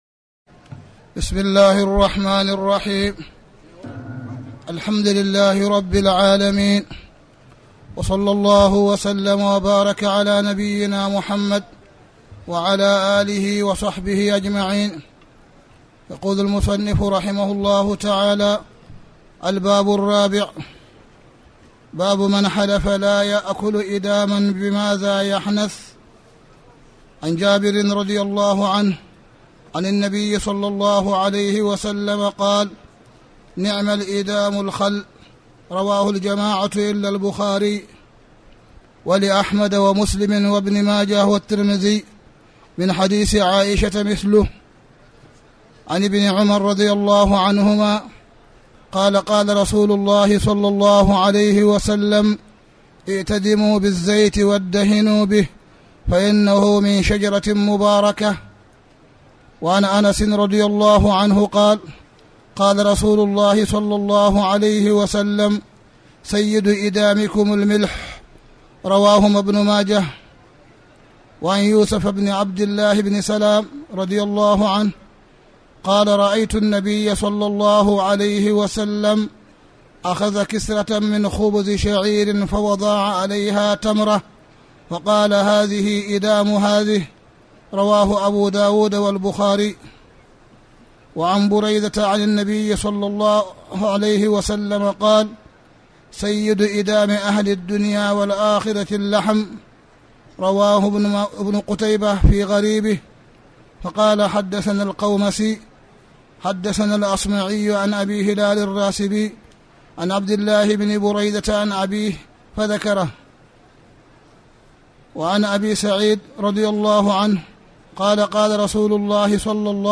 تاريخ النشر ٢٤ رمضان ١٤٣٩ هـ المكان: المسجد الحرام الشيخ: معالي الشيخ أ.د. صالح بن عبدالله بن حميد معالي الشيخ أ.د. صالح بن عبدالله بن حميد كتاب الأيمان